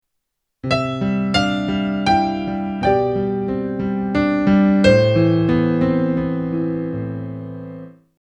In the previous example, the song was in the key of C Major.
Clearly adding the extra notes from the scale as we moved from the E in bar one to the G in bar 2 has added some more interest to the melody.